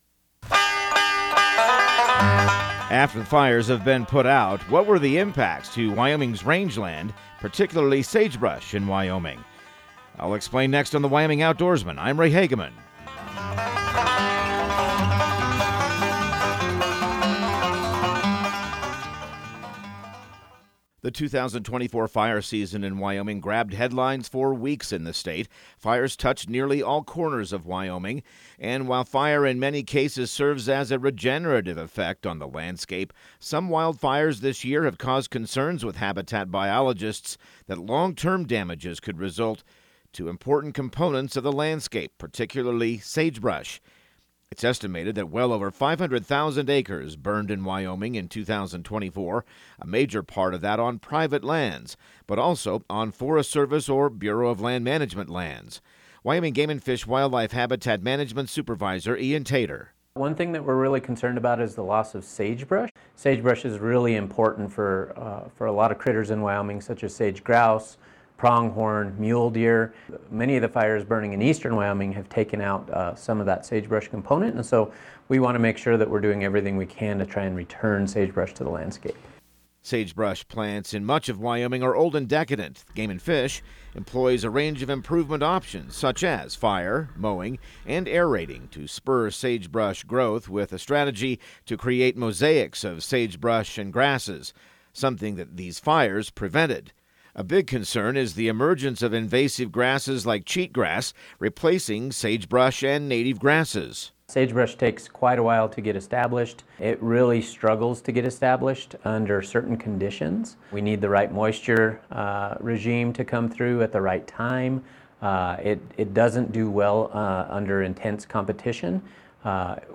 Radio news | Week of November 25